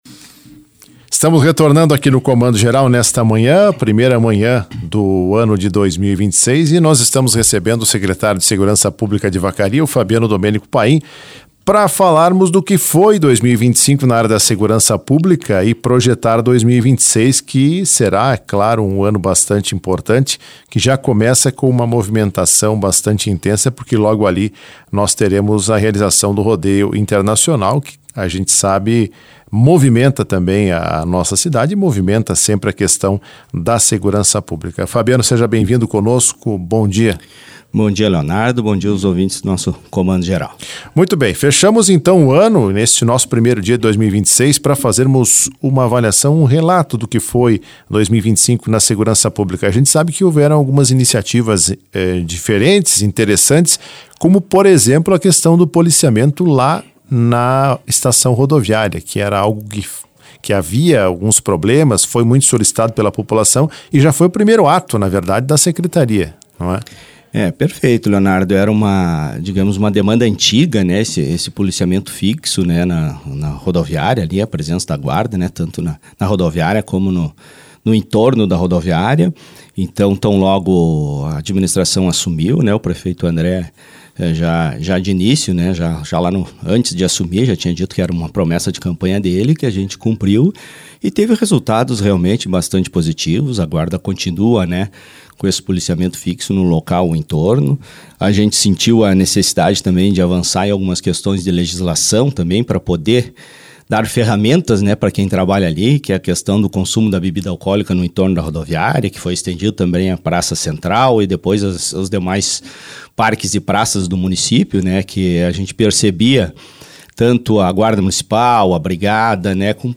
Em entrevista à Rádio Esmeralda nesta sexta-feira, 02, ele afirmou que diversas iniciativas foram tomadas e comentou sobre uma antiga demanda da comunidade, que foi a atuação ostensiva da Guarda Municipal no entorno da estação rodoviária.